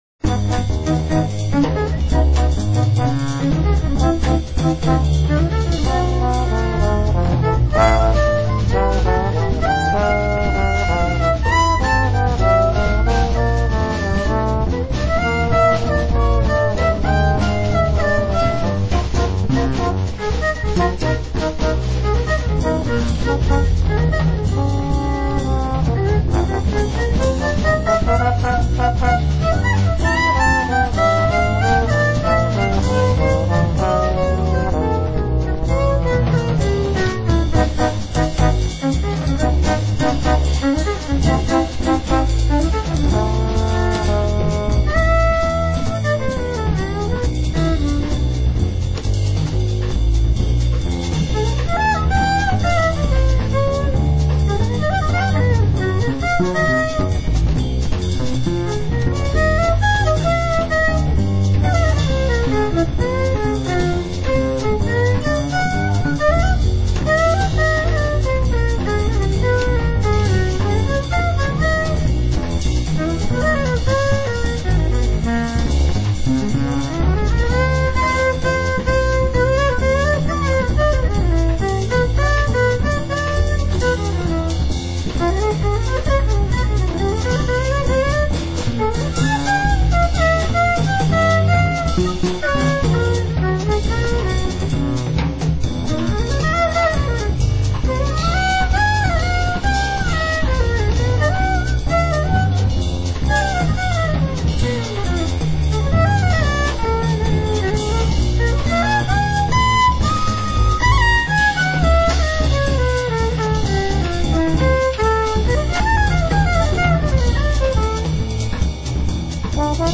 violin, percussions, vocal
trombone
doublebass
drums
at The Mascherona Studio, Genova - Italy